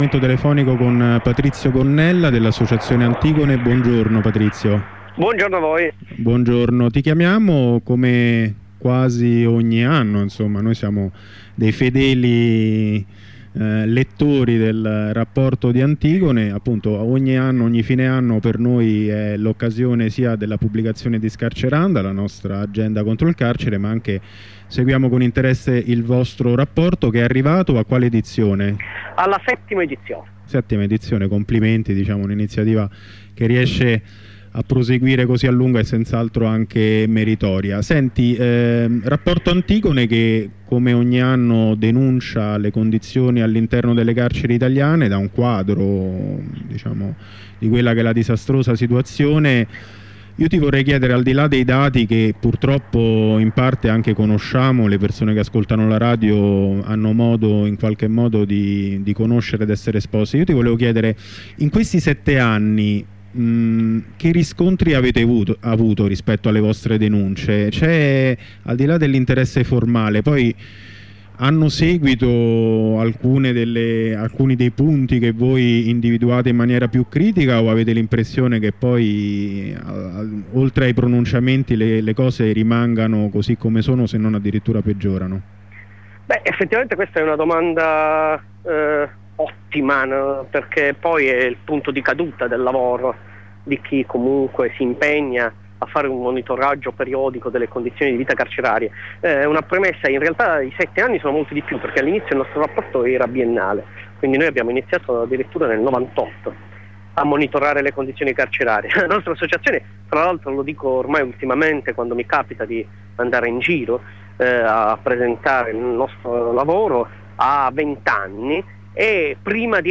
Studentessa della Sapienza